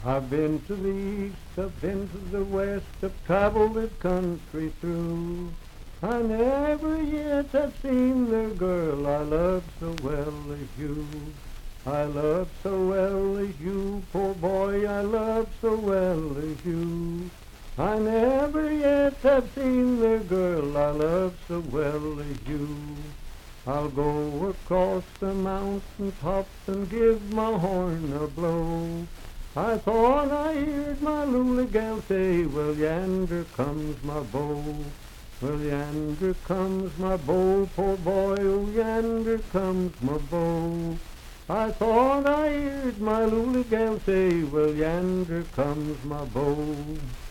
Unaccompanied vocal music
Verse-refrain 2(8).
Voice (sung)
Franklin (Pendleton County, W. Va.), Pendleton County (W. Va.)